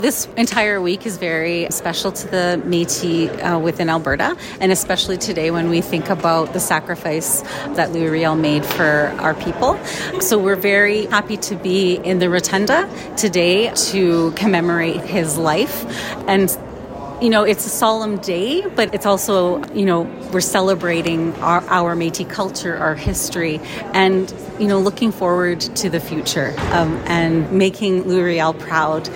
Windspeaker Radio Network spoke with Madame President of the Métis Nation of Alberta Andrea Sandmaier. She said that many of the Métis citizens where very happy to be inside the rotunda within the Alberta Legislature.